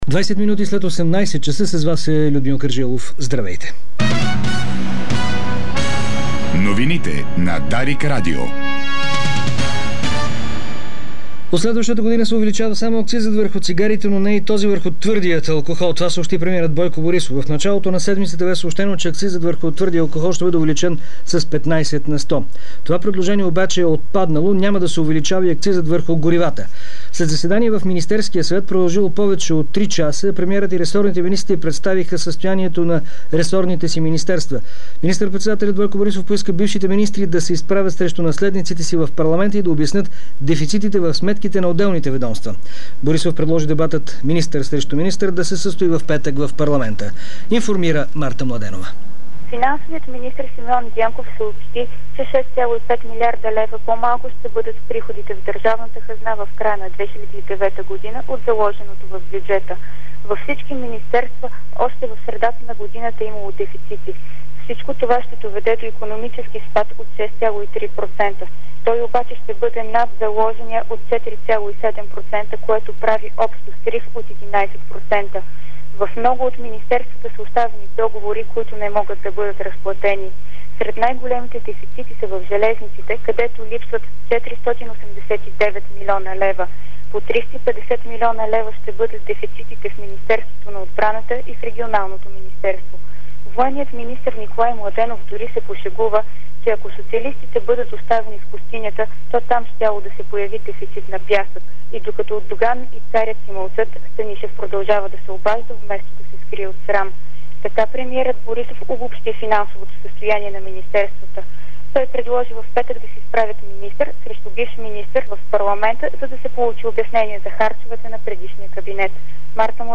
Обзорна информационна емисия - 11.10.2009